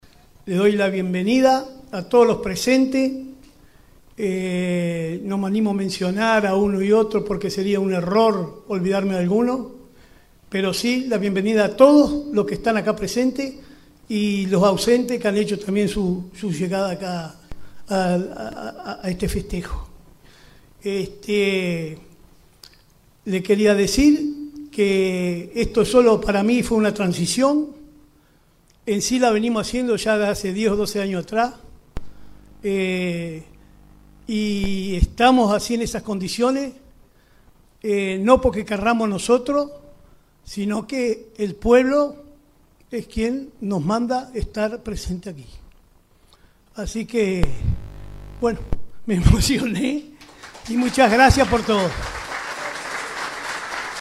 El primero en hacer uso de la palabra fue el alcalde saliente, Martín Barla, quien agradeció la presencia de los asistentes y destacó el carácter de continuidad en la gestión municipal.